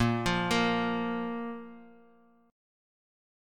Bb5 chord